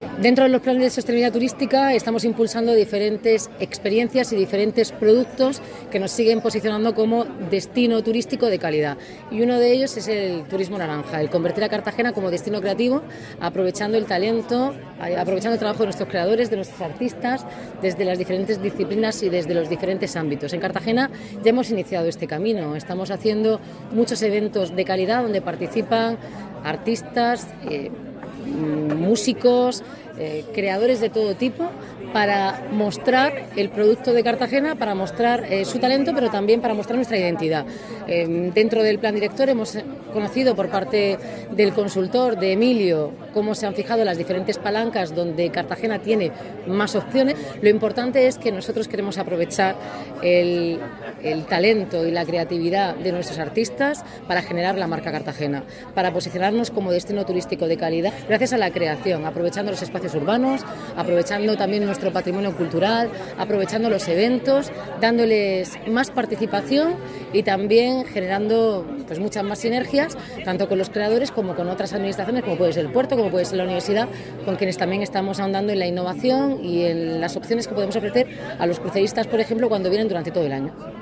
Enlace a Declaraciones de Noelia Arroyo sobre Estrategia Turismo Creativo